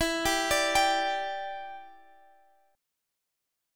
G5/E chord